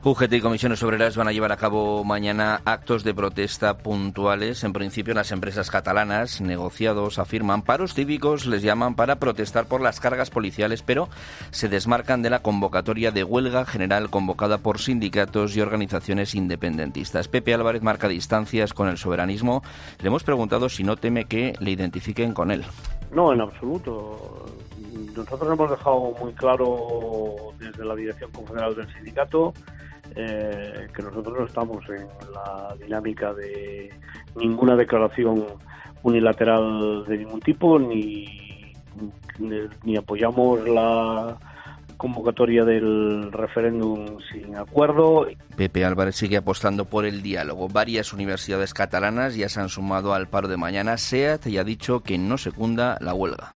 Pepe Álvarez ha aclarado en COPE cual va a ser la postura de su sindicato ante la huelga convocada.